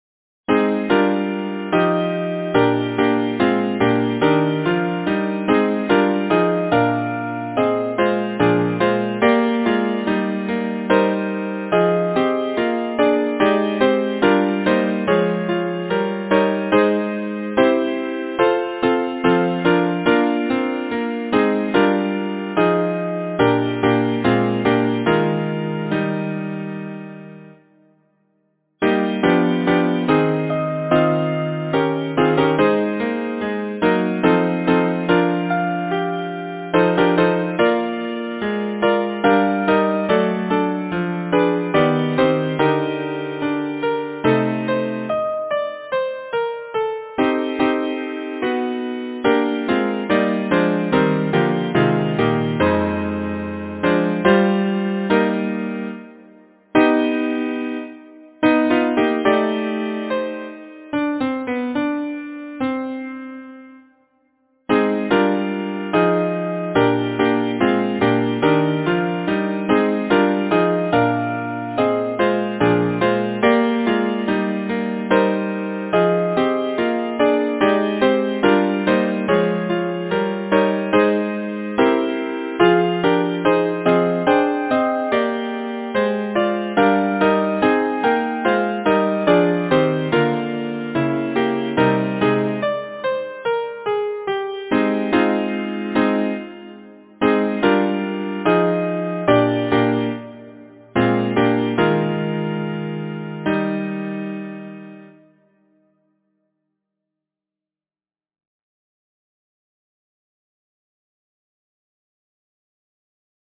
Title: Chloris in the Snow Composer: Percy Eastman Fletcher Lyricist: William Strode Number of voices: 4vv Voicing: SATB Genre: Secular, Partsong
Language: English Instruments: A cappella